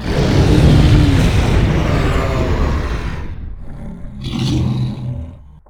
combat / creatures / dragon / he / die1.ogg
die1.ogg